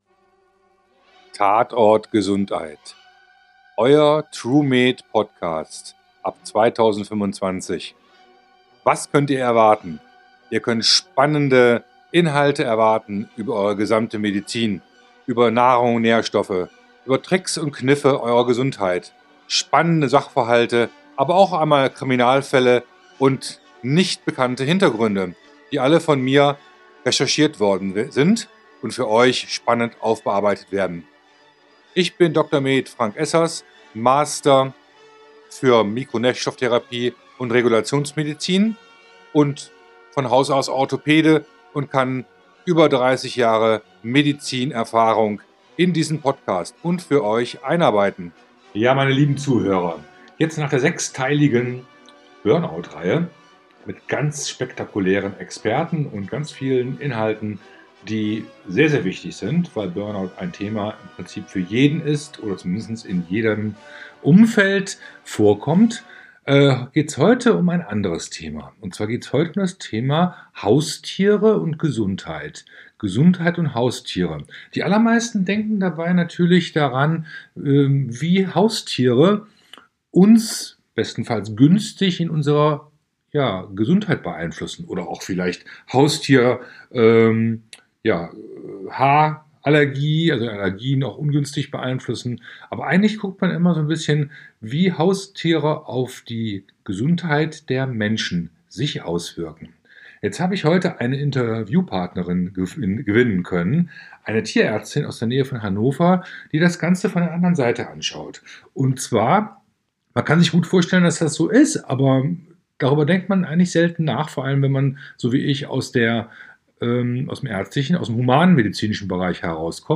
Tier-Mensch Mensch-Tier-Achse, Eine tiefe Gesundheitsverbindung: Halter-Tier, Tier-Halter Experten-Interview Teil1, #39